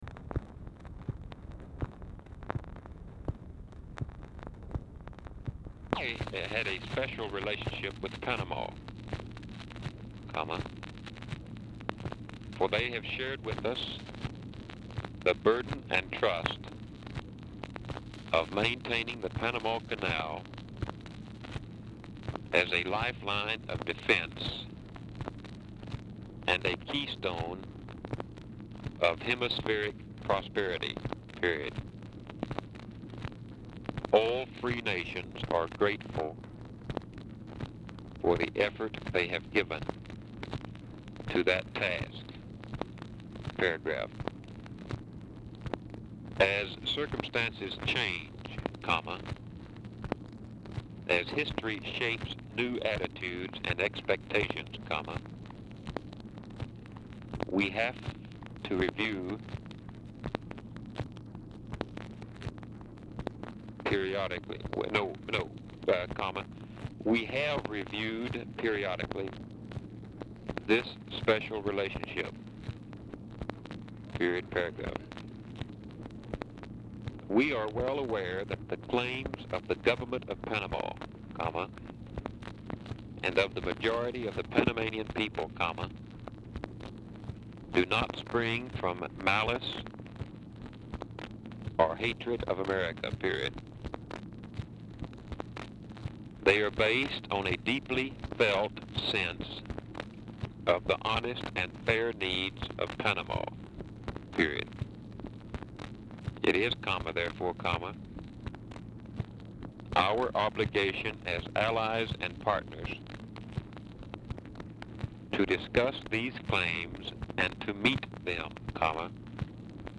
Telephone conversation # 2583, sound recording, LBJ and DEAN RUSK, 3/21/1964, 12:16PM
Format Dictation belt
Location Of Speaker 1 Oval Office or unknown location